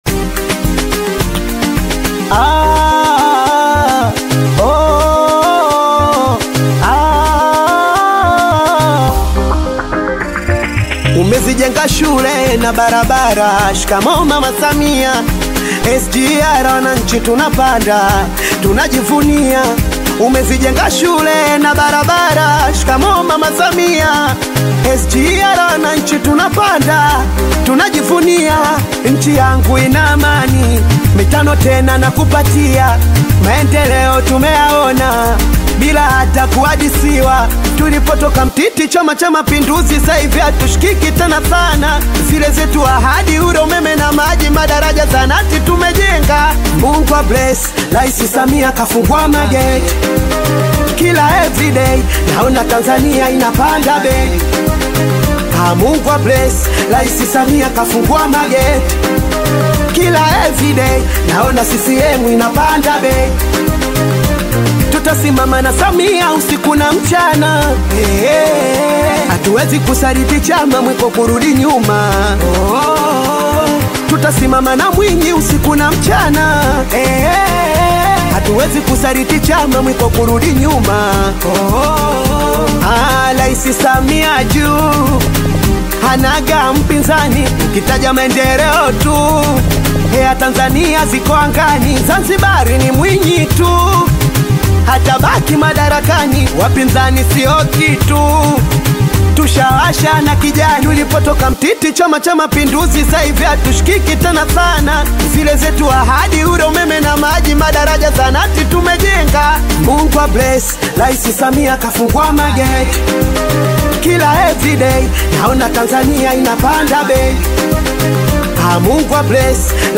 energetic Singeli anthem
Genre: Singeli